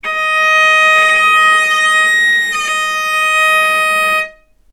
vc_sp-D#5-ff.AIF